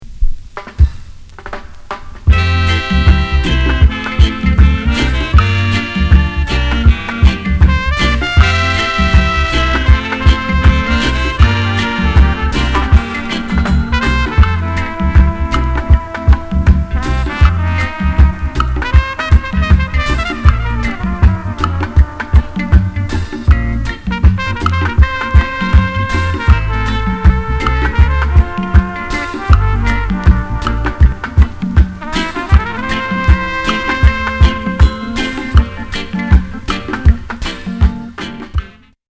トロンボーン（？）インスト・レゲー・カバー！